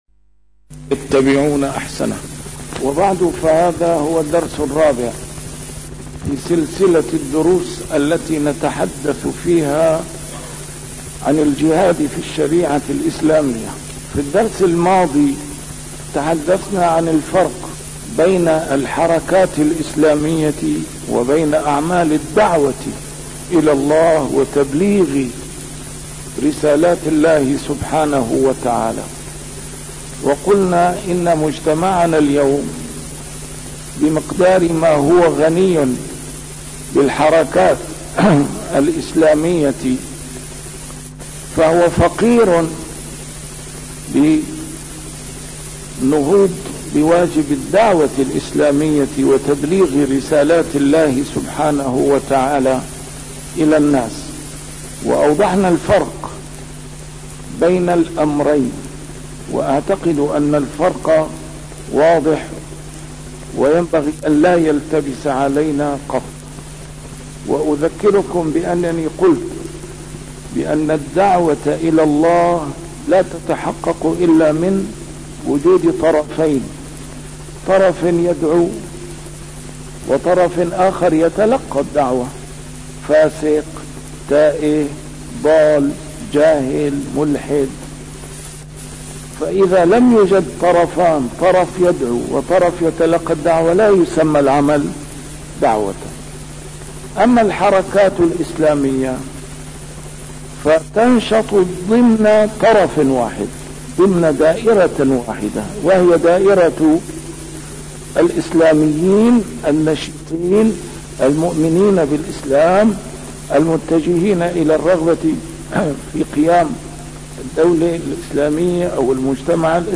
A MARTYR SCHOLAR: IMAM MUHAMMAD SAEED RAMADAN AL-BOUTI - الدروس العلمية - الجهاد في الإسلام - تسجيل قديم - الدرس الرابع: مسؤولية الدعوة - ضوابطها وأهدافها